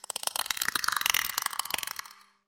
Alien Chittering
Rapid insectoid alien chittering with clicking mandibles and eerie harmonic overtones
alien-chittering.mp3